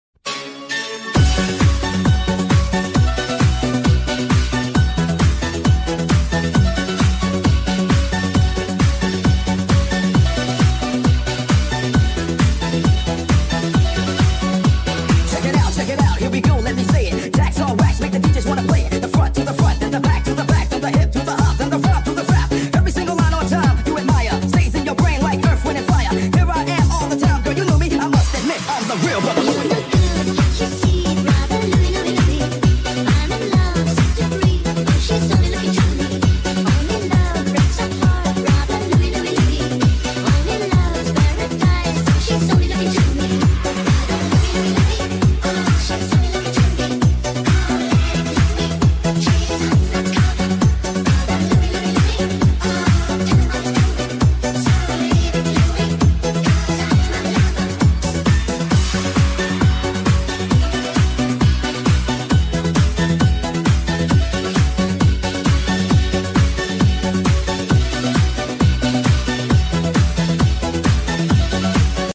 (speed up)